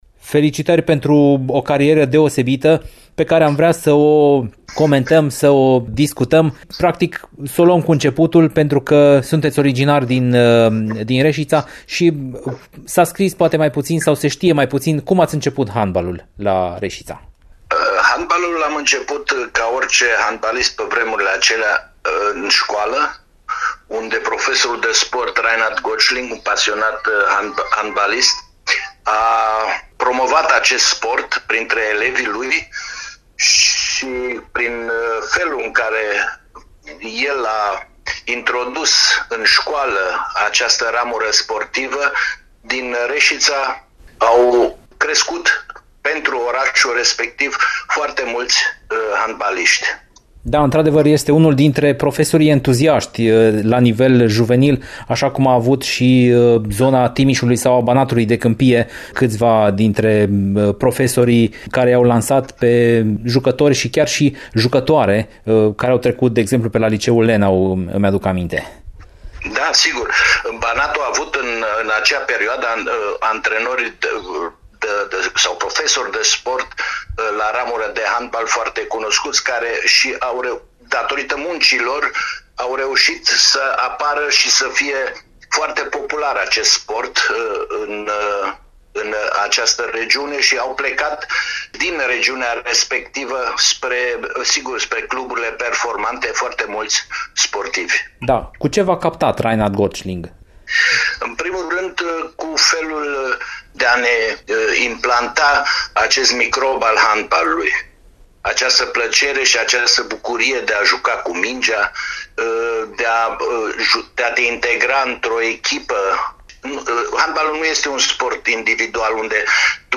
Werner Stöckl, originar din Reșița, împlinește marți (28 iunie) o vârstă rotundă – 70 de ani – acesta fiind prilejul pentru o discuție despre cariera de jucător, ce a început la Reșița și a avut apogeul la Steaua București și mai ales la echipa națională de seniori.